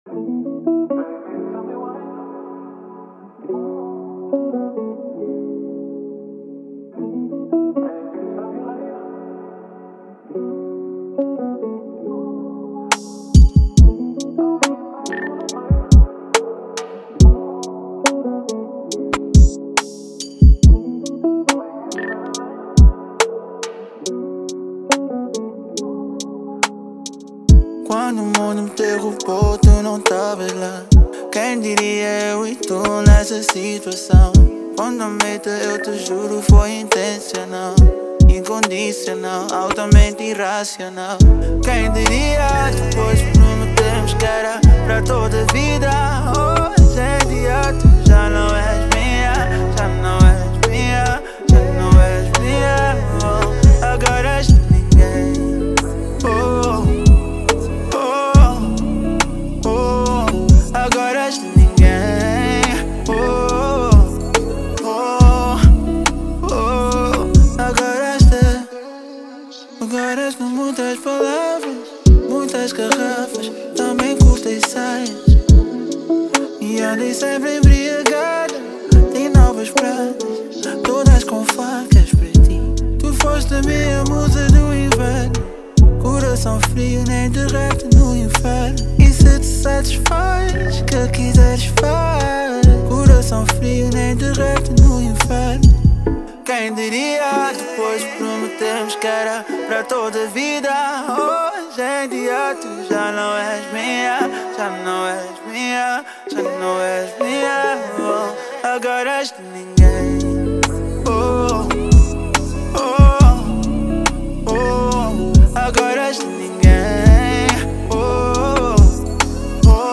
R&B
Dessa vez numa sonoridade mais suave